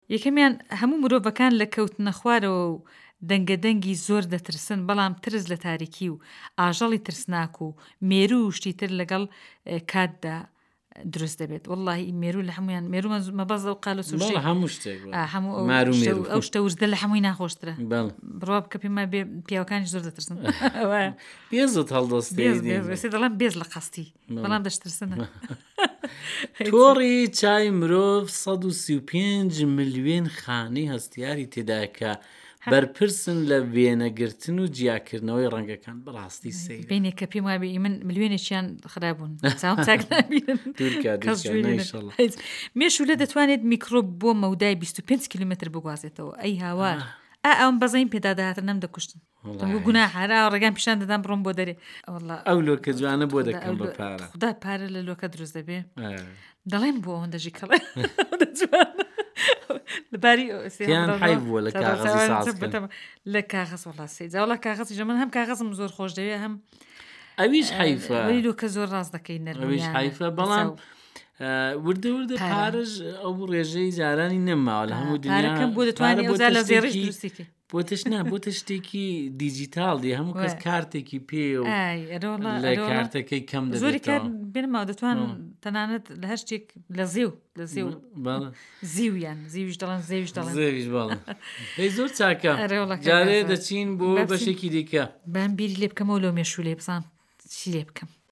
وتووێژێکی خۆش لە نێوان پێشکەشکارانی بەرنامەی گزنگی بەیانی